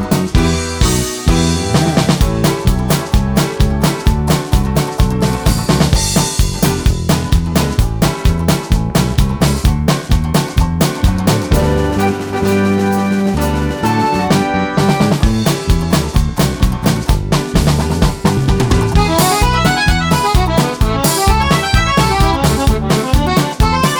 Minus Ukulele T.V. Themes 2:17 Buy £1.50